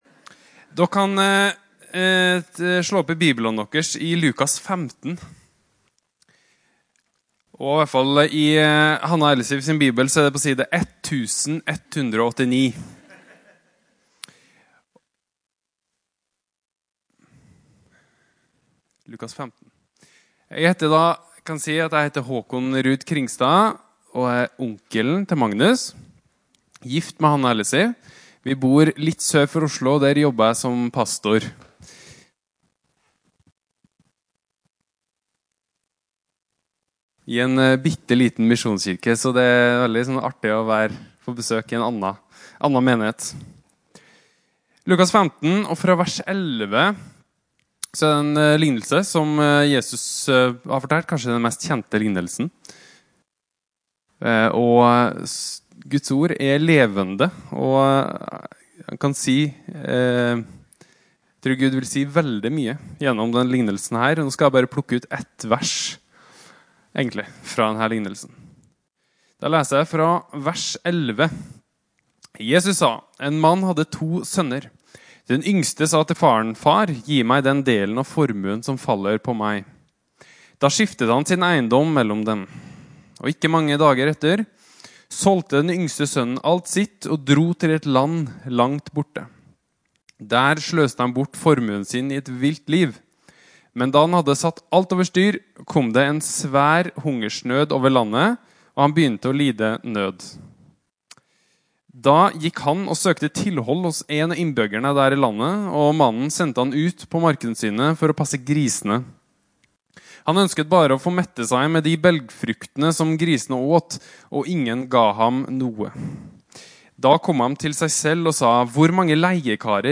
Opptak av tale